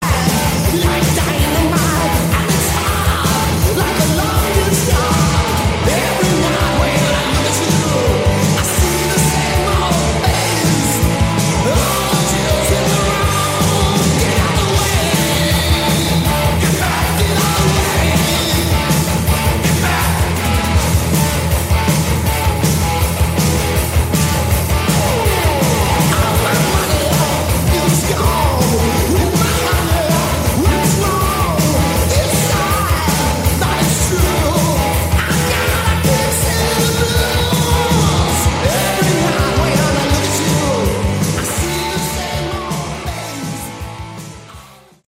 Category: Hard Rock
vocals, guitar
bass
drums